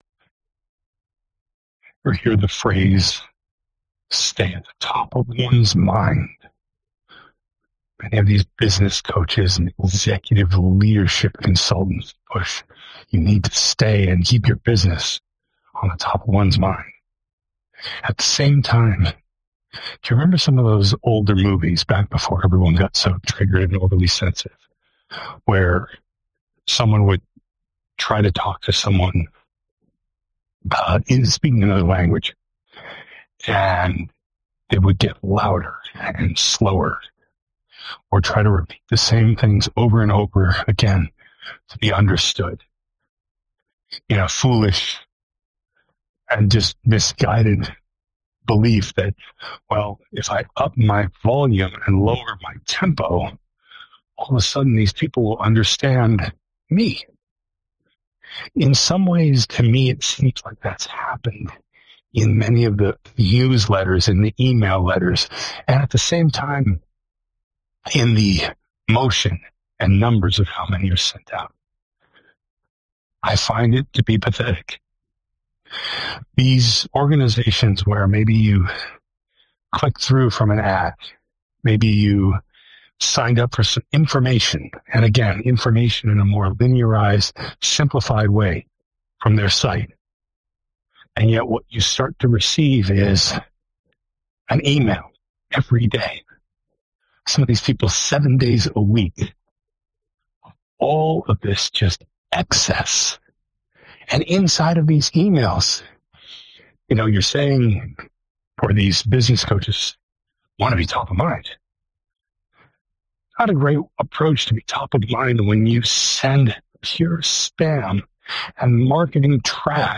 Top of Mind Bottom Feeders Original Audio Rant.